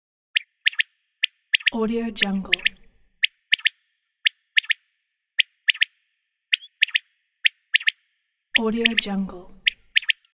Quail Efecto de Sonido Descargar
Quail Botón de Sonido